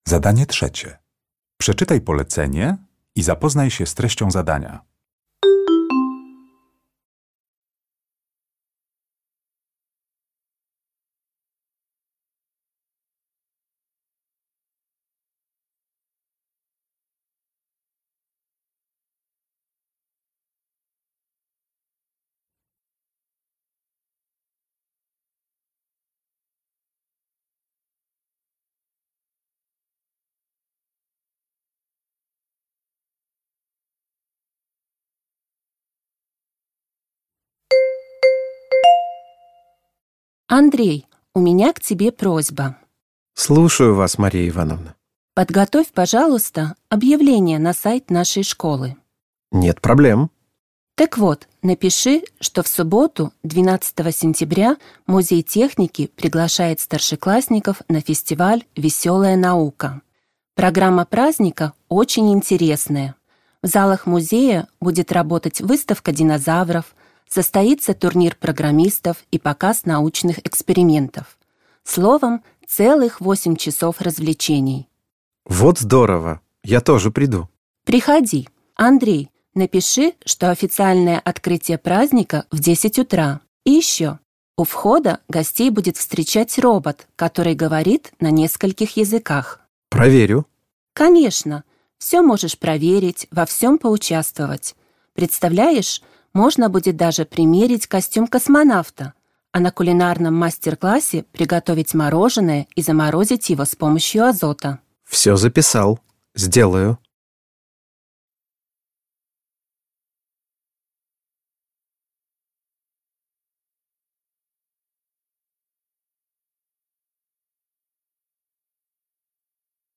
Uruchamiając odtwarzacz z oryginalnym nagraniem CKE usłyszysz dwukrotnie rozmowę nauczycielki z uczniem.